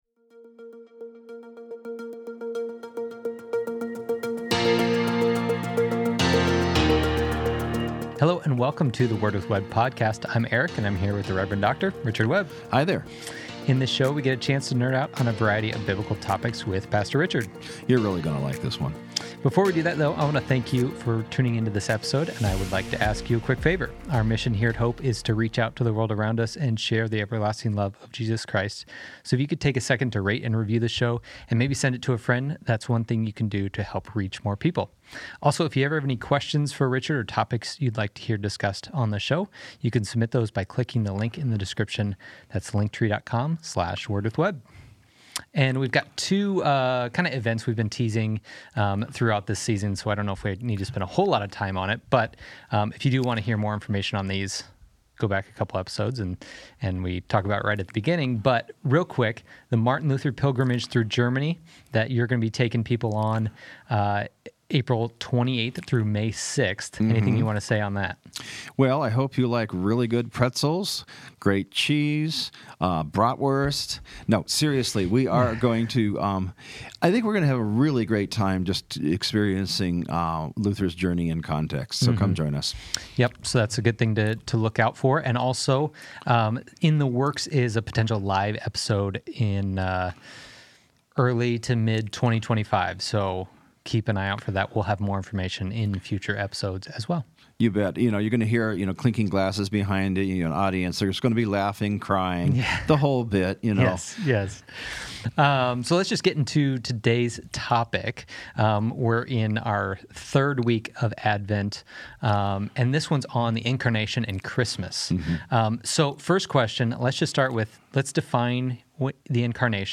Sem a necessidade de instalar ou se inscrever Q&R. Buddhism.
weekly discussions on topics you've always wanted to dig deeper into.